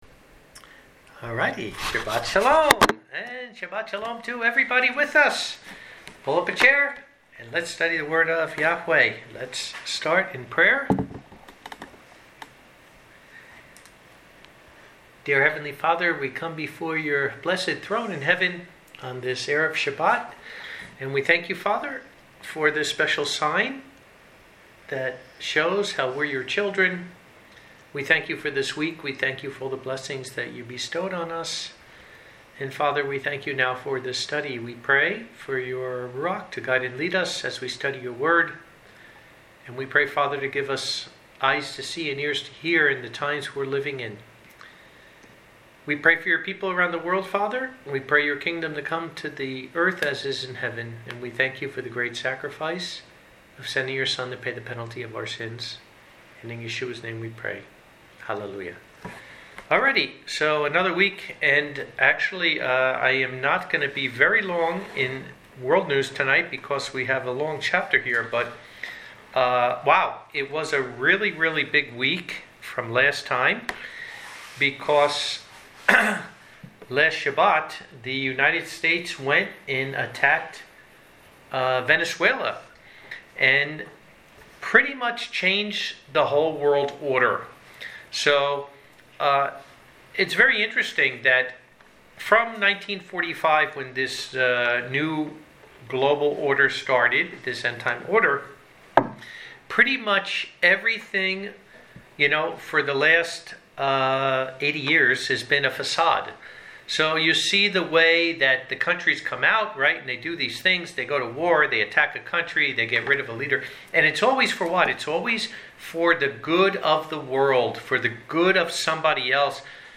Bible Study_ 2 Tim 2.mp3